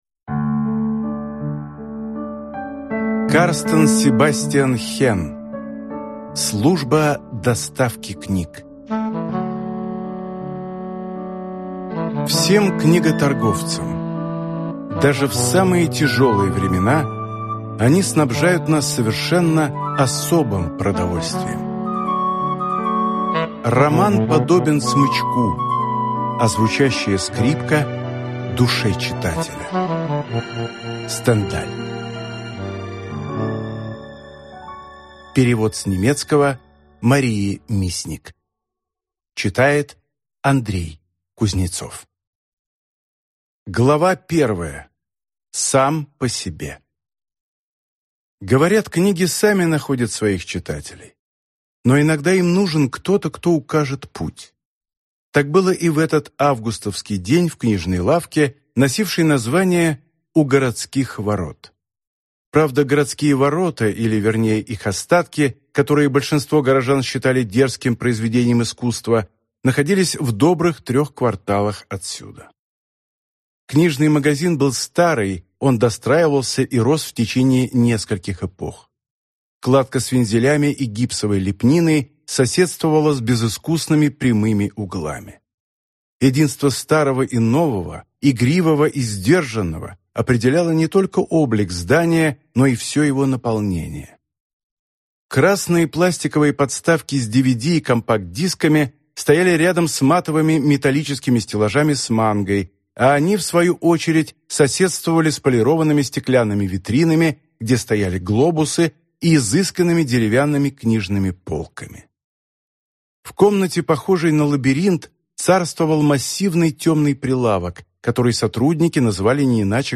Аудиокнига Служба доставки книг | Библиотека аудиокниг